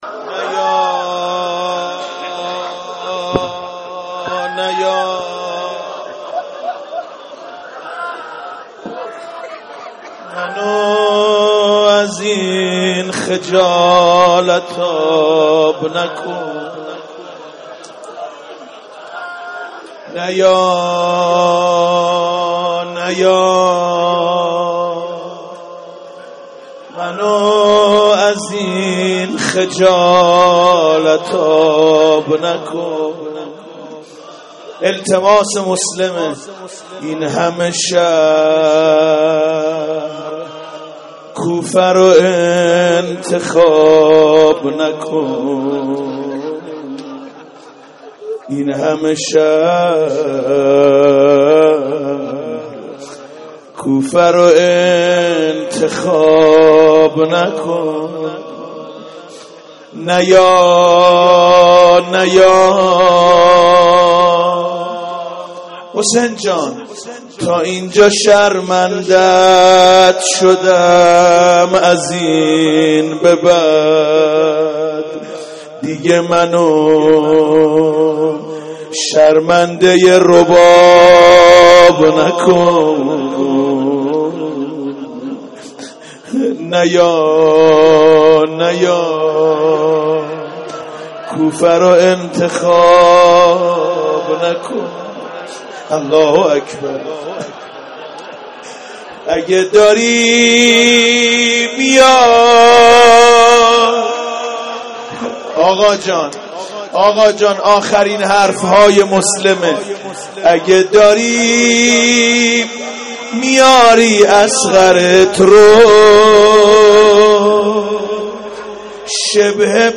مداحی روز اول محرم 1402